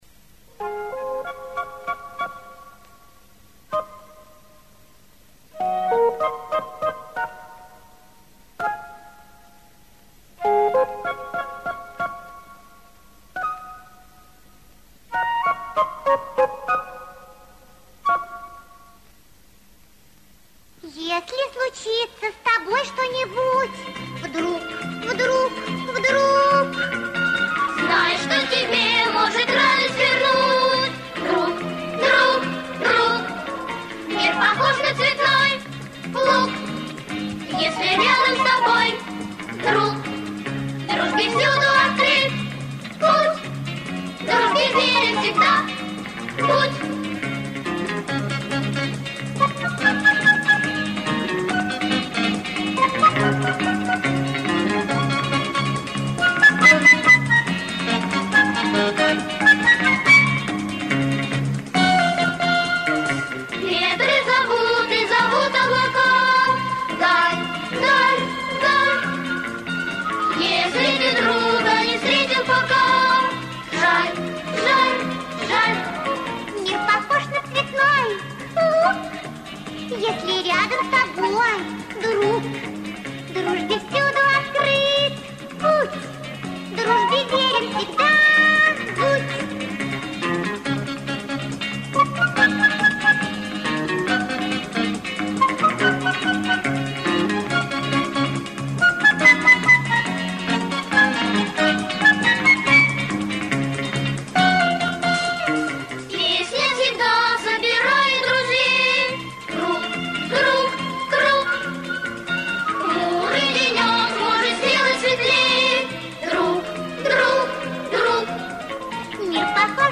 Детская хоровая песня о дружбе